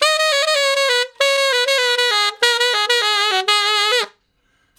068 Ten Sax Straight (Ab) 05.wav